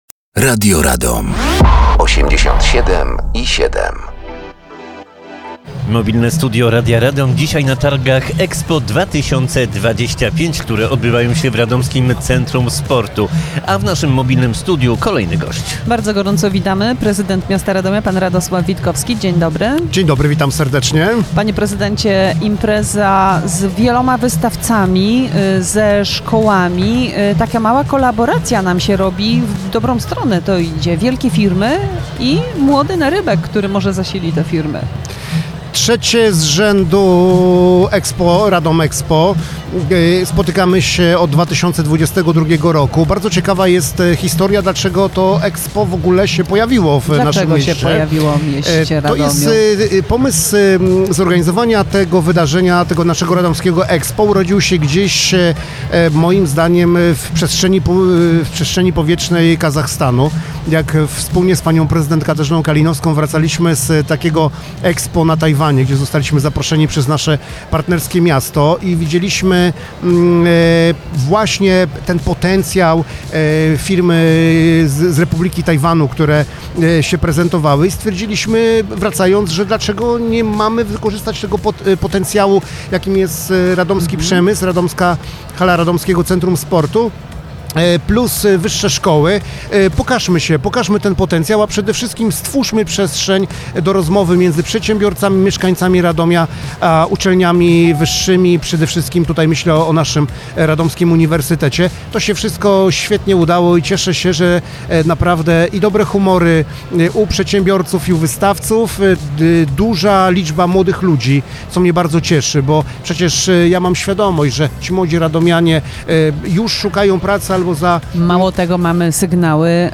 Trwają Targi EXPO 2025.
Gościem Mobilnego Studia Radia Radom był Radosław Witkowski Prezydent Radomia